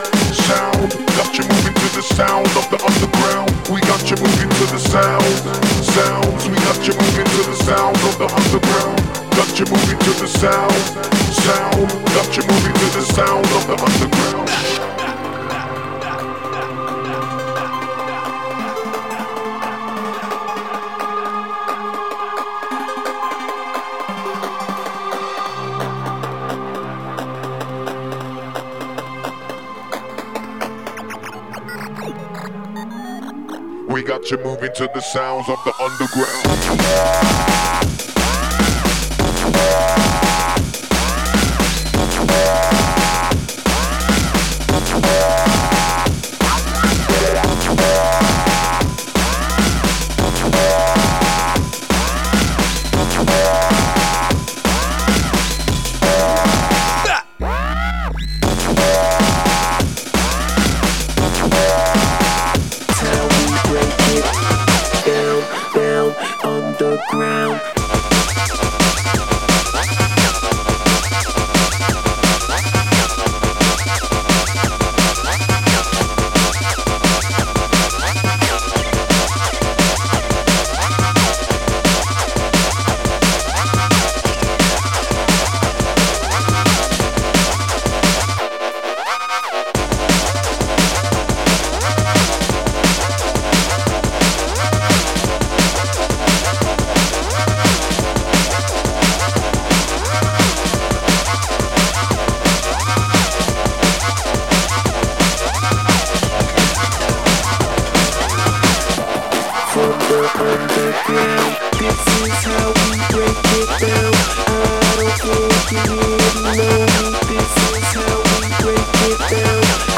Drum N Bass Tags